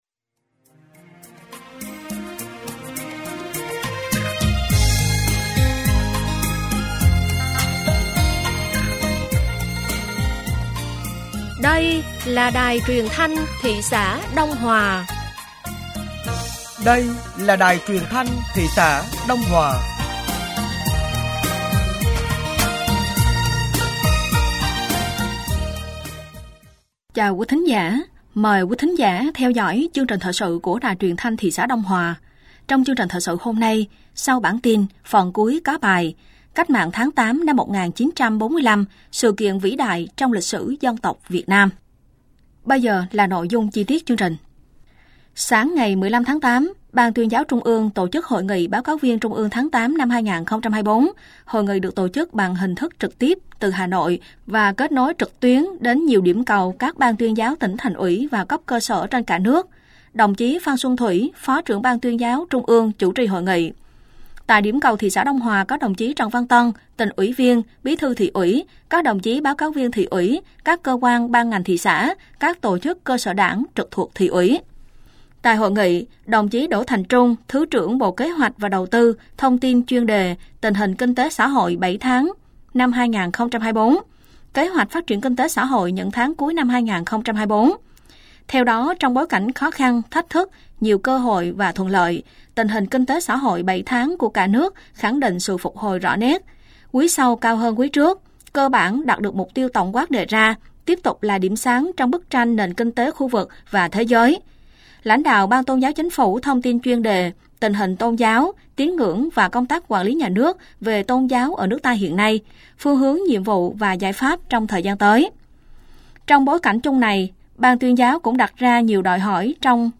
Thời sự tối ngày 15 và sáng ngày 16 tháng 8 năm 2024